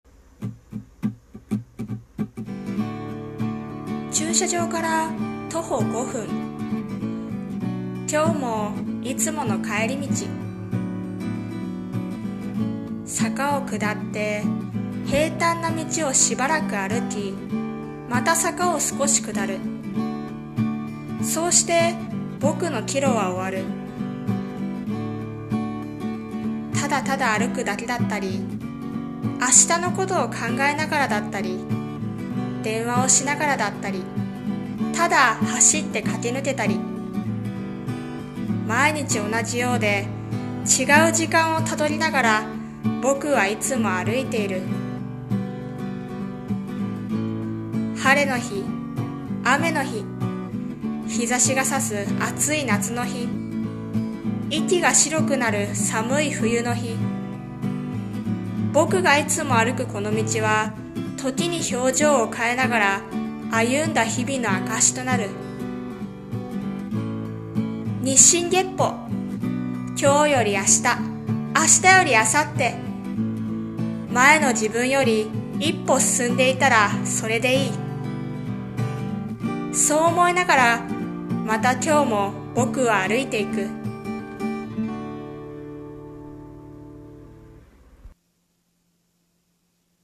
さんの投稿した曲一覧 を表示 【朗読台本】 さて、歩こうか